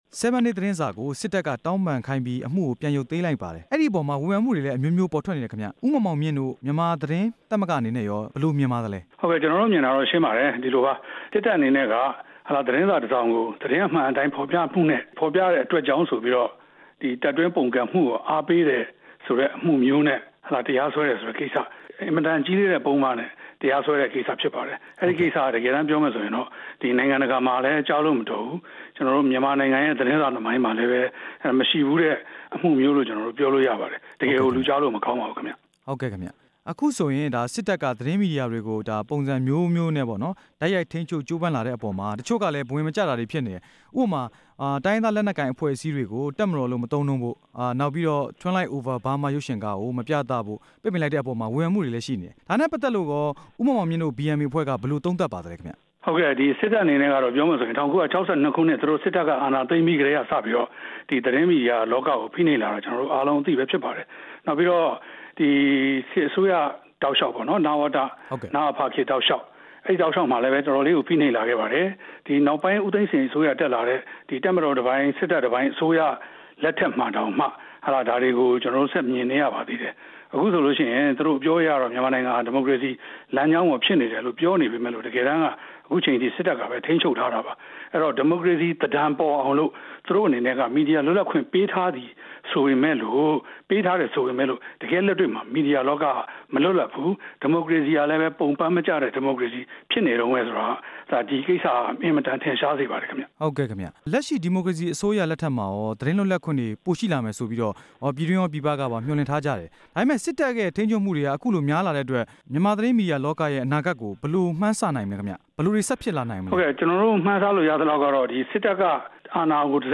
7 Day သတင်းစာကို စစ်တပ်က တောင်းပန်ခိုင်းပြီး အမှုရုပ်သိမ်းတဲ့ကိစ္စ BMA အဖွဲ့နဲ့ မေးမြန်းချက်